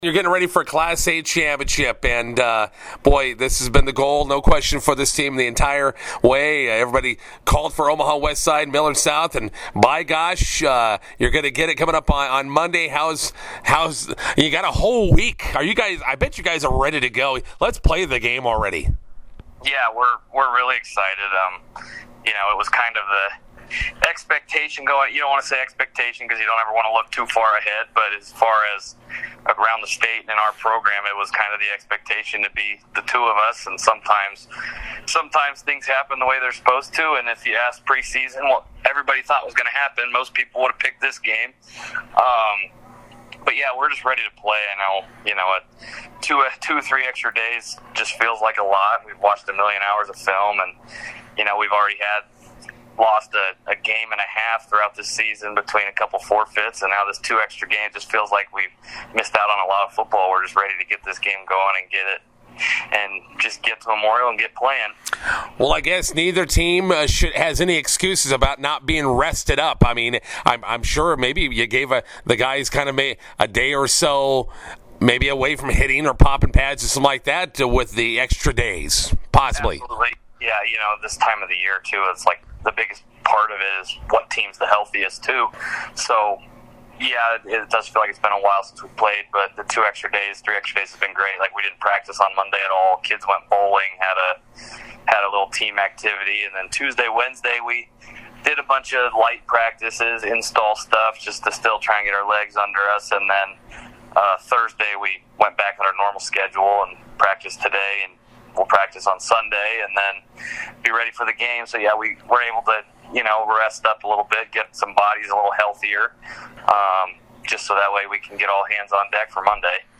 INTERVIEW: Omaha Westside, Millard South battle for Class A football title tonight in Lincoln.